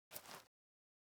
Illusion-UE5/Dirt Walk - 0004 - Audio - Dirt Walk 04.ogg at 8b99f468325d76551cc0082ab8c992cf189538bb